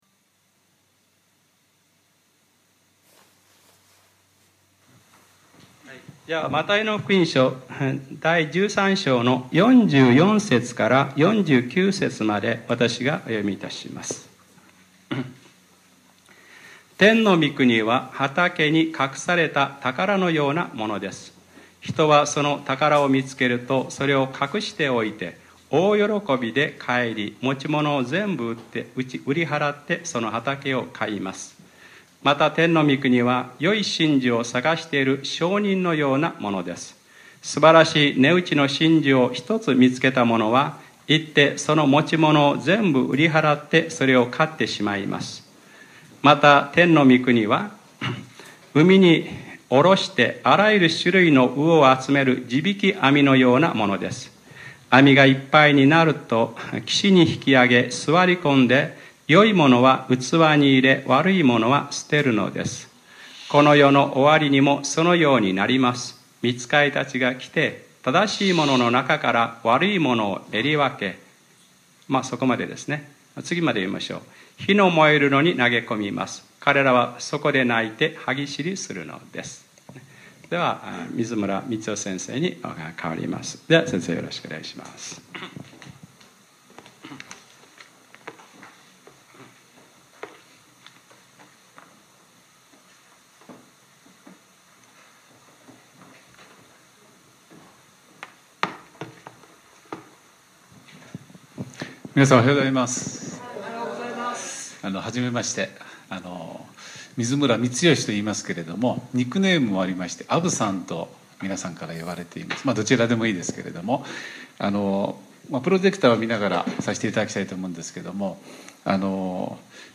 2015年08月23日（日）礼拝説教 『７つの本質と実践』